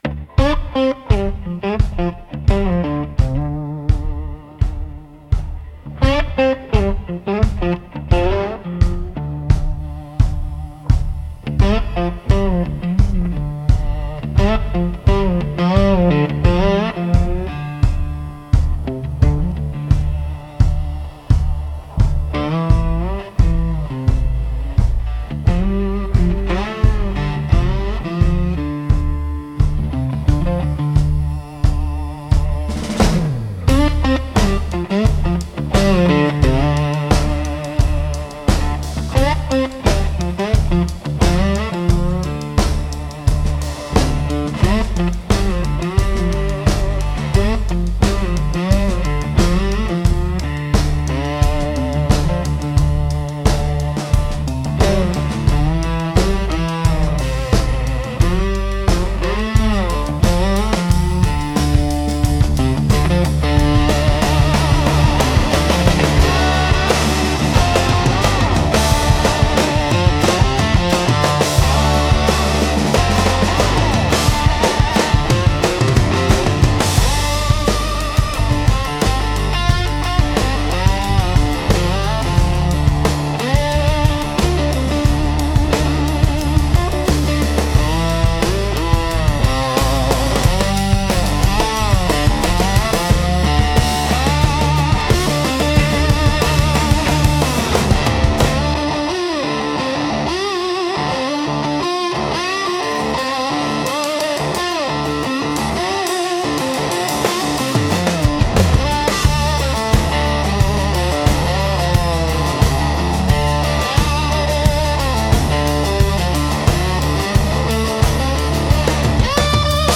Instrumental - Thunderfoot Shuffle 3.50